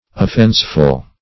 Search Result for " offenseful" : The Collaborative International Dictionary of English v.0.48: Offenseful \Of*fense"ful\, a. Causing offense; displeasing; wrong; as, an offenseful act.